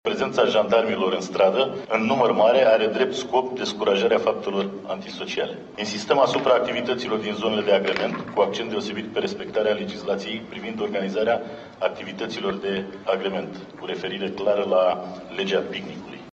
Iar cei care nu fac curățenie, în urma lor riscă să fie amendați, pentru că vor fi controale în aceste zile, spune seful Jandarmieriei Române, Marin Andreiana: